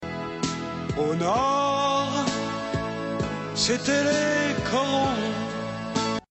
Bewilderment Emote Animal Crossing